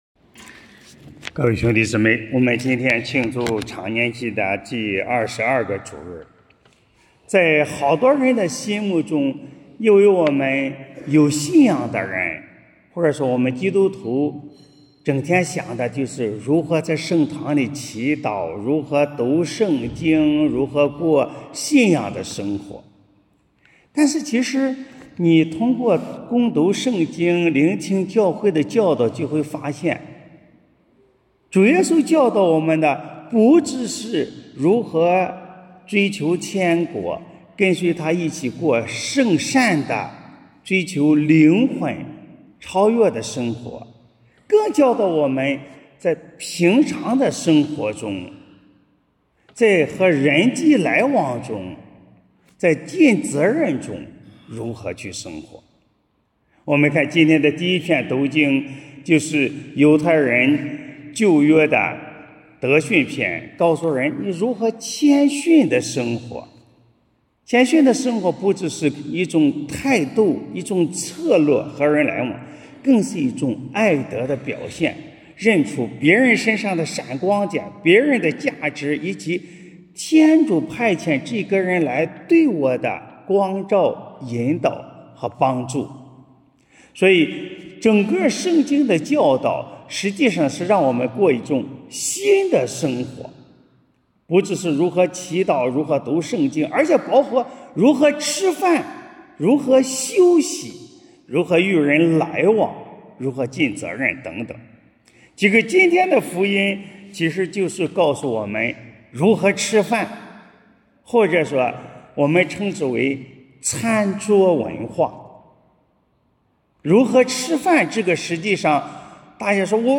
【主日证道】| 餐桌尽显真信仰（丙-常年期第22主日）